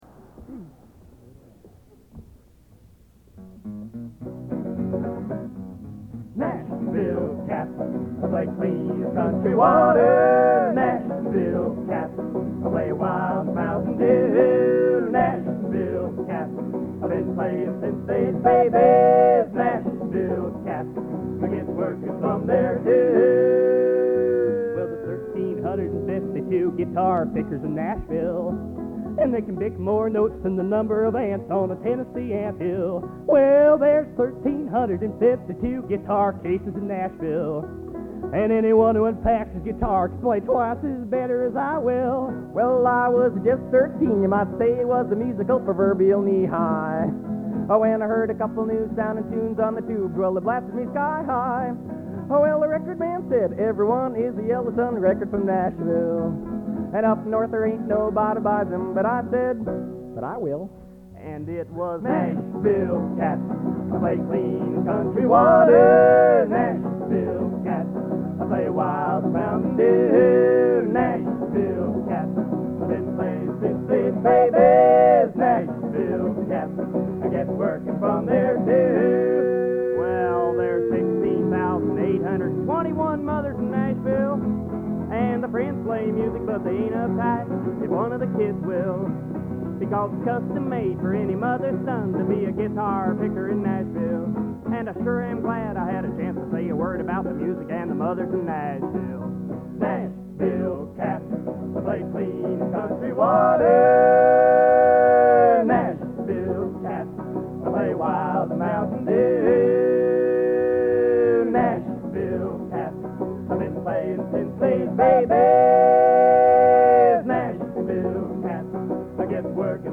Genre: | Type: End of Season